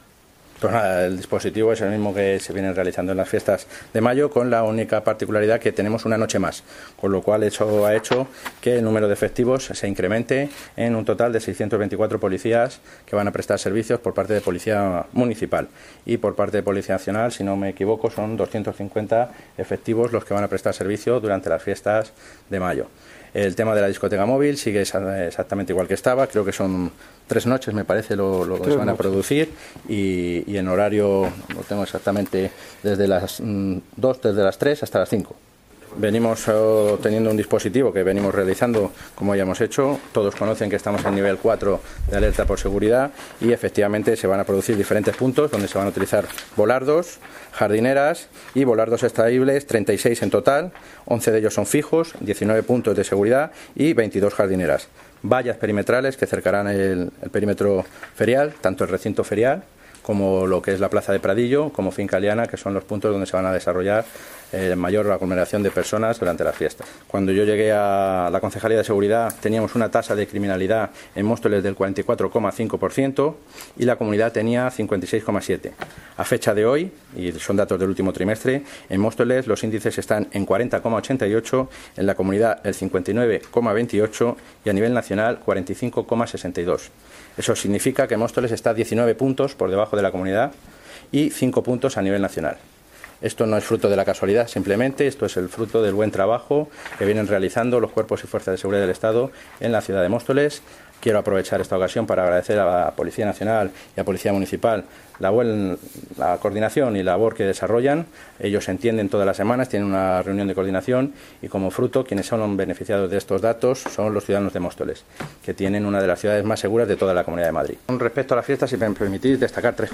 Audio - Roberto Sánchez (Concejal de Seguridad Ciudadana) Sobre seguridad Fiestas 2 de Mayo 2019